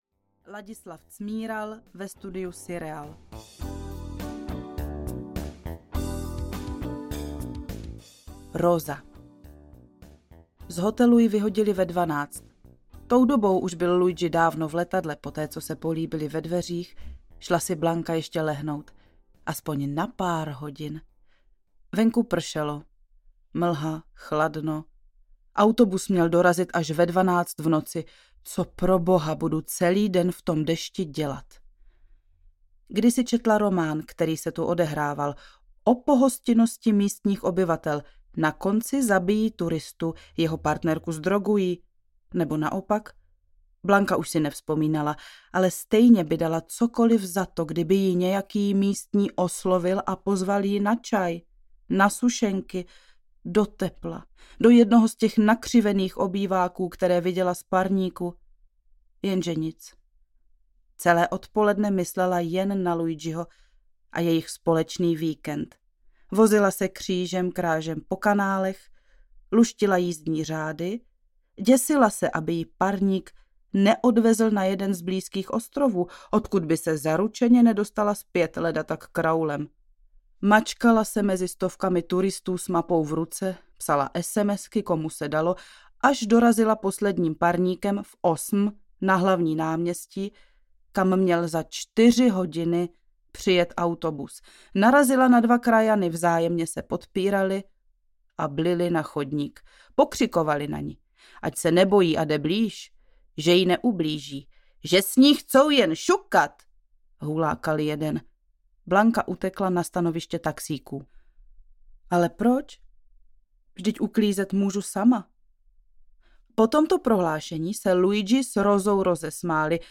Toxo audiokniha
Ukázka z knihy